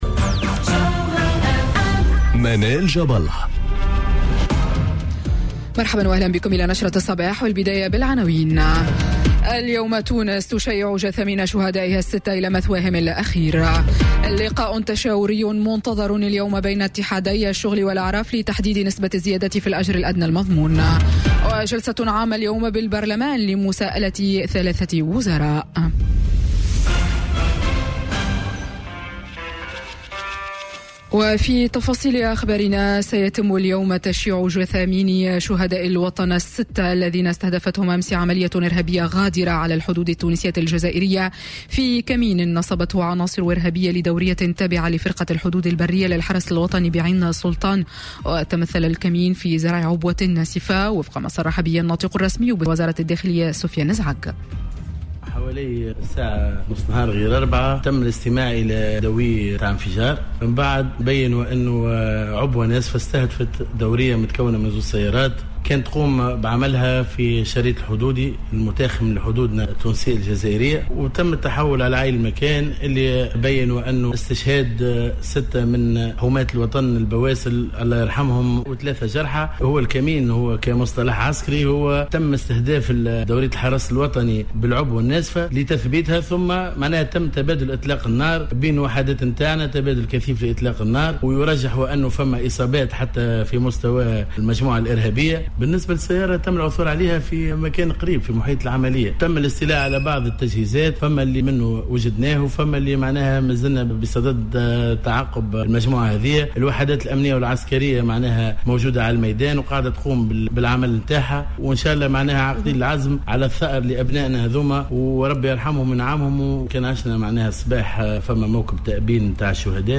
نشرة أخبار السابعة صباحا ليوم الاثنين 9 جويلية 2018